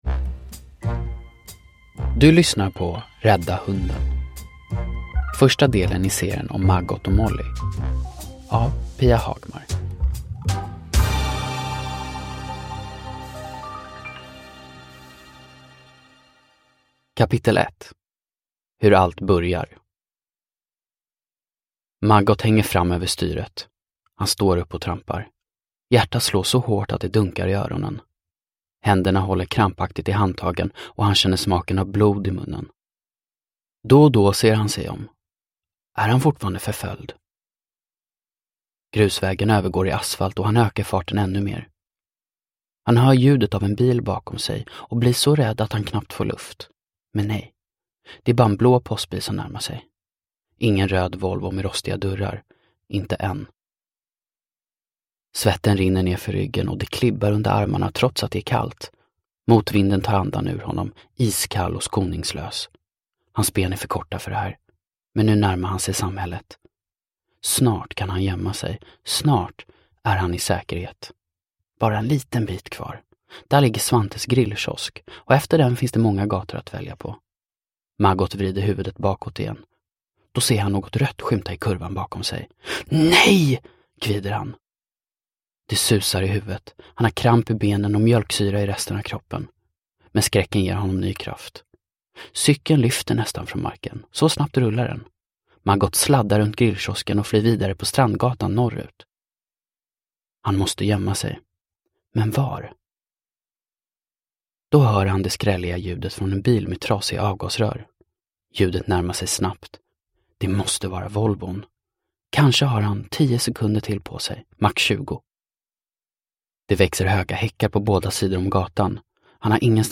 Rädda hunden – Ljudbok – Laddas ner